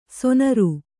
♪ sonaru